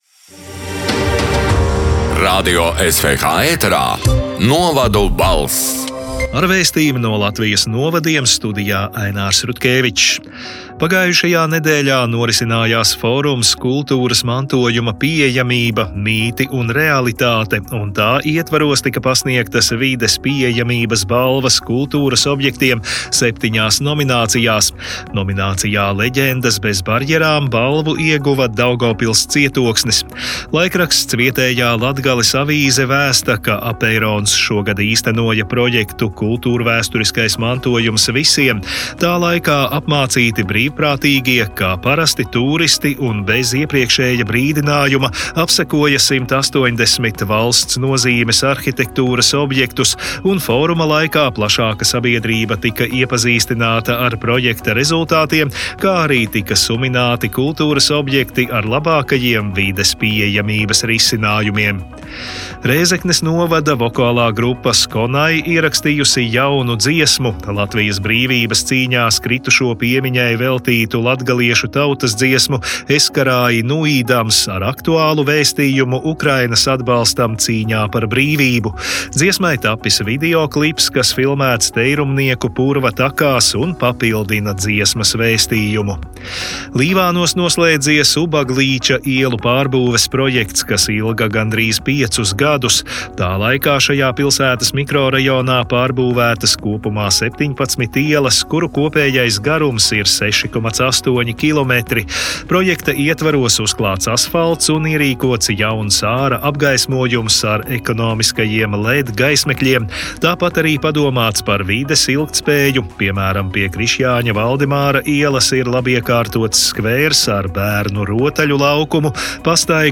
“Novadu balss” 15. novembra ziņu raidījuma ieraksts: